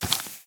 Minecraft Version Minecraft Version snapshot Latest Release | Latest Snapshot snapshot / assets / minecraft / sounds / mob / husk / step3.ogg Compare With Compare With Latest Release | Latest Snapshot
step3.ogg